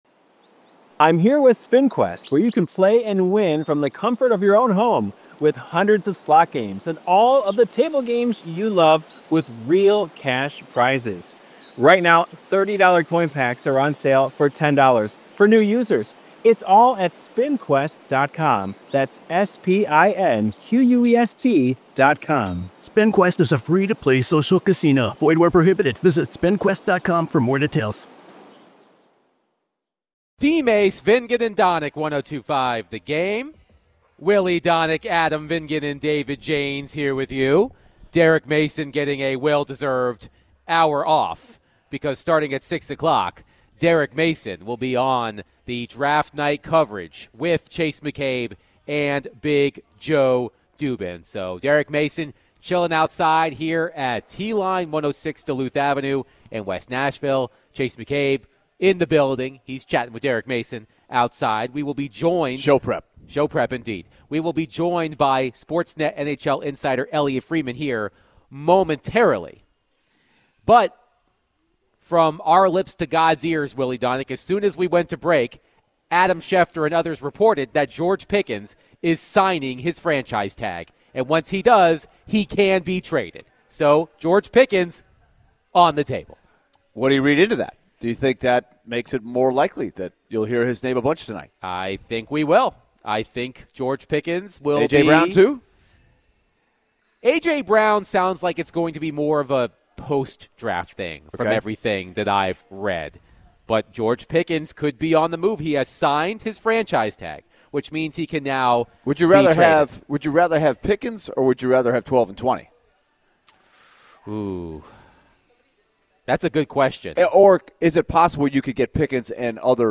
NHL Insider Elliotte Friedman joined DVD to discuss the Nashville Predators GM search, Stanley Cup Playoffs, and more.